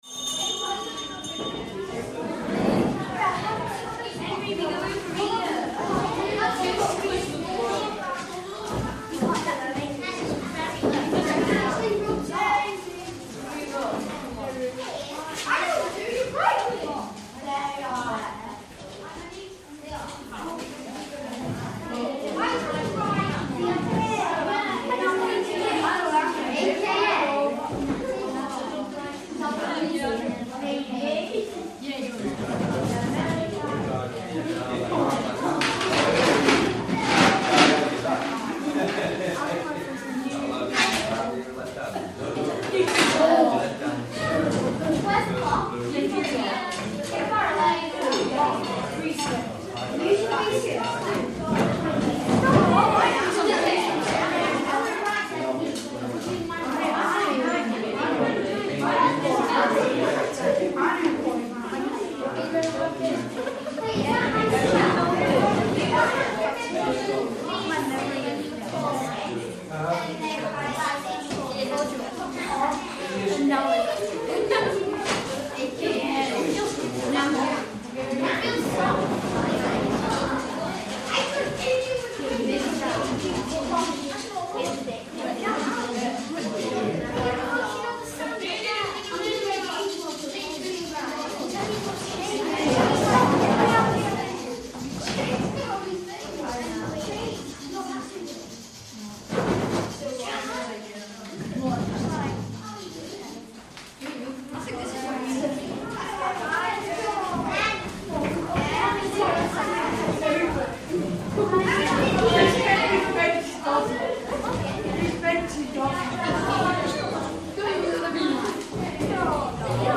Quarry Bank Mill - Murmurs of children in the school house (part of the 'sounds' series)